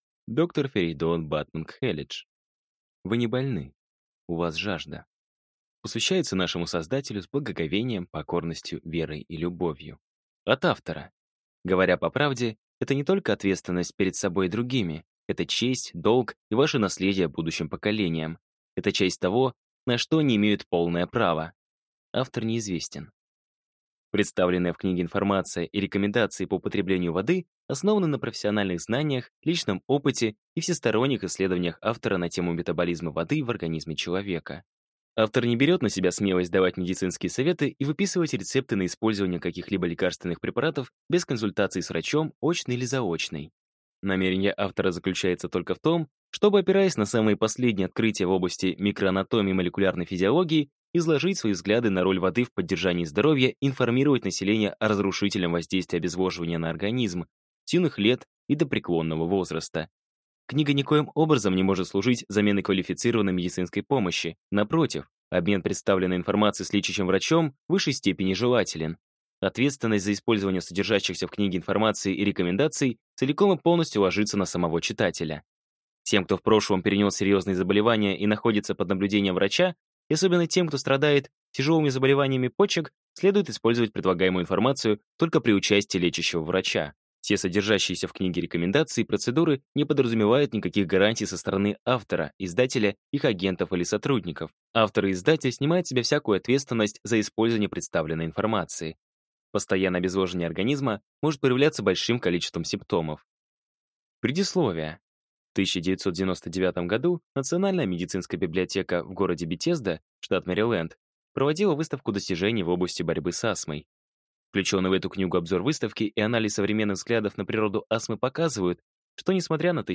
Аудиокнига Вы не больны, у вас жажда | Библиотека аудиокниг